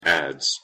Pronunciation En Adze